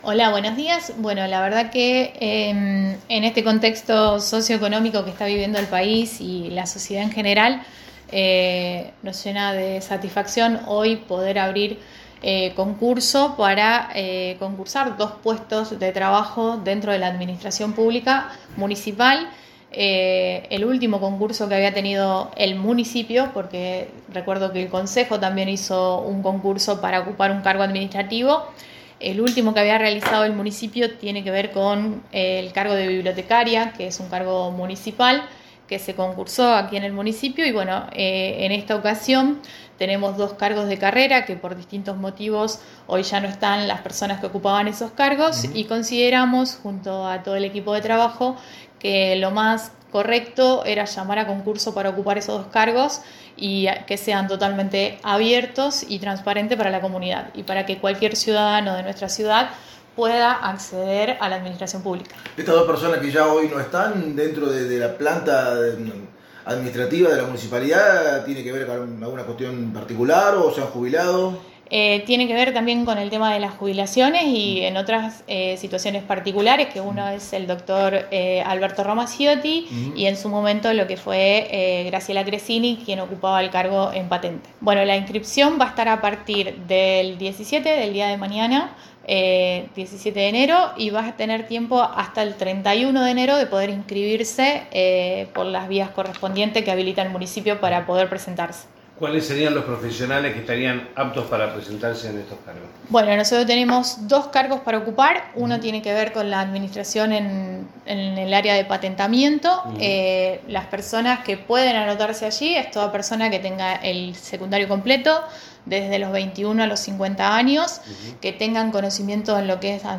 En la mañana de hoy estuvimos conversando con la señorita daniela Pérez, secretaria de gobierno de la municipalidad de Armstrong sobre el llamado  a Concurso de cargos Administrativos.
daniela Pérez – Secretaria de Gobierno municipalidad de Armstrong